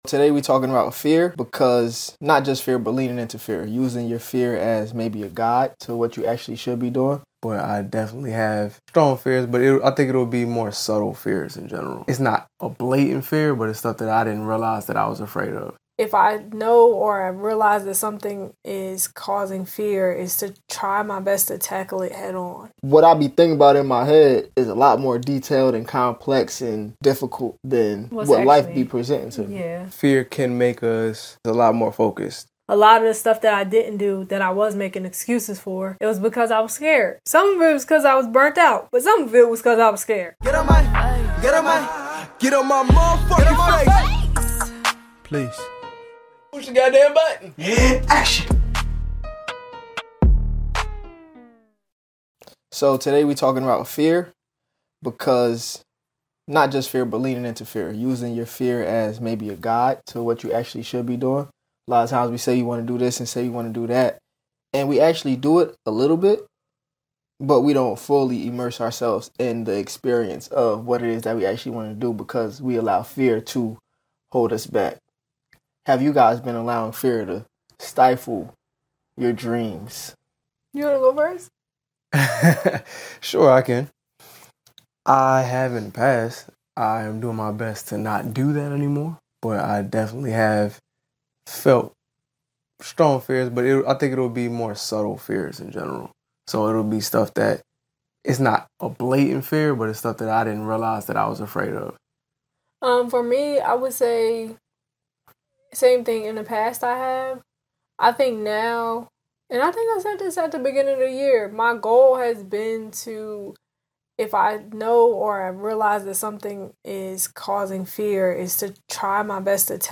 Our purpose is to create great vibes, great music, and great conversation.